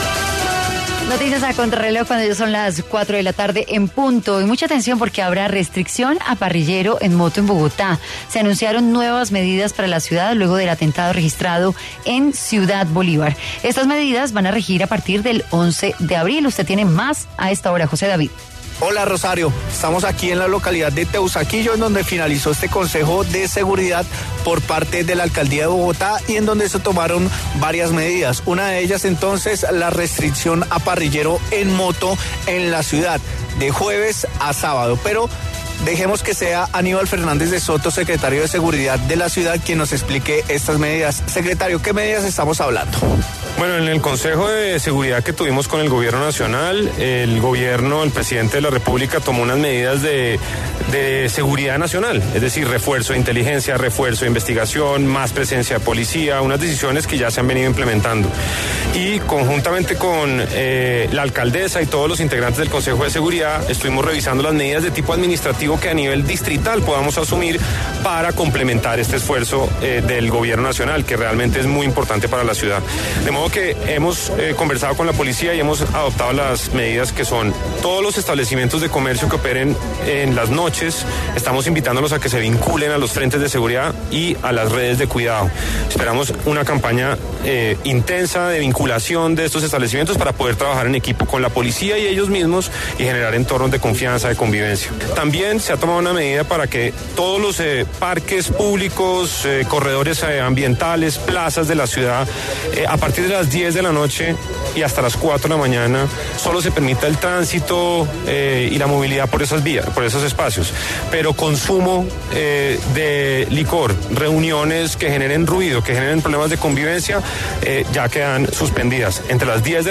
En diálogo con W Radio, Aníbal Fernández de Soto, secretario de Seguridad, explicó las medidas que según el funcionario, empezarán a regir tan solo desde el 11 de abril, por lo que en los próximos días, habrá jornada pedagógicas para explicar la razones.